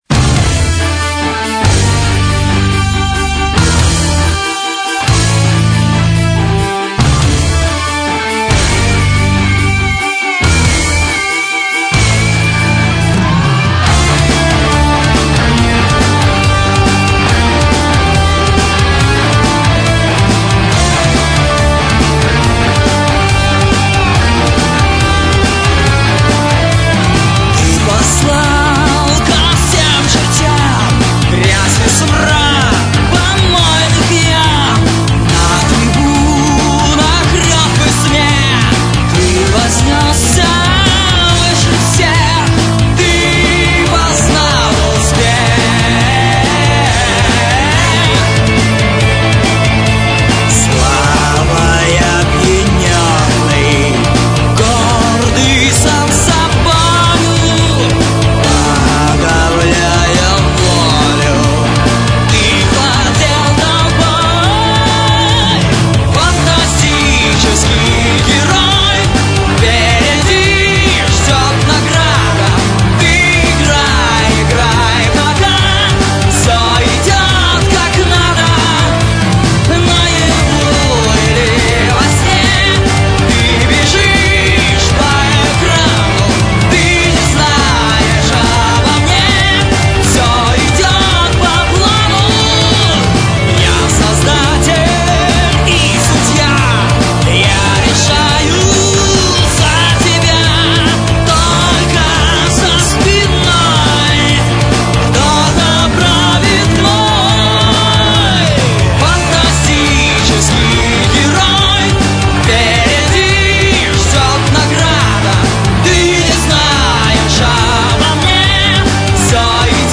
Metal
выдержан в духе мелодичного ретро heavy metal
вокал
бас-гитара
гитара
клавишные
барабаны